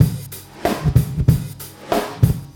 Backward.wav